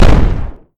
bfg_fire.ogg